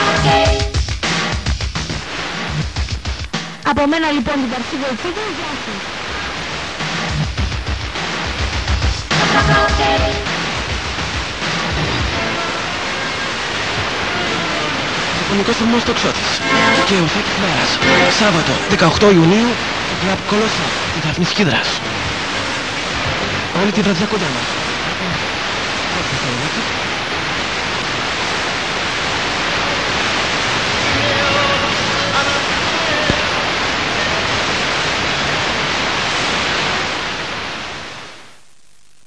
I think I have heard this same station in mid 1990's under name Radiofonikos stathmos Toxiatis on 96.7 MHz (